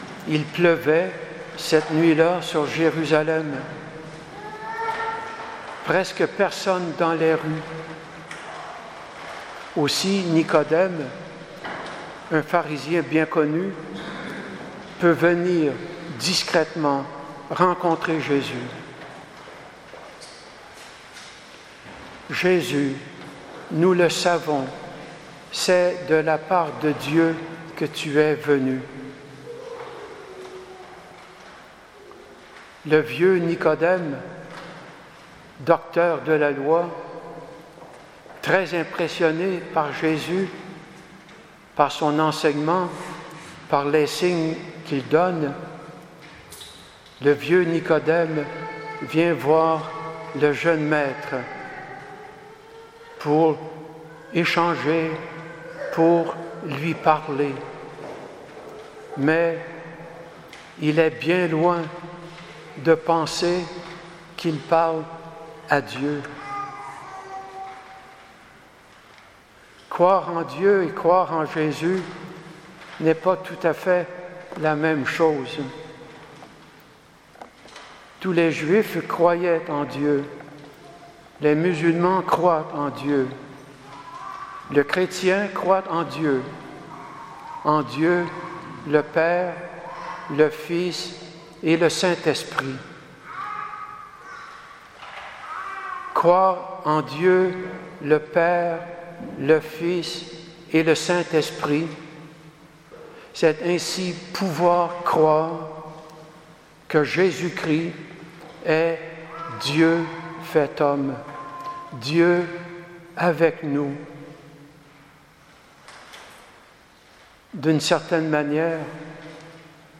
Homélie du 4ème dimanche de Carême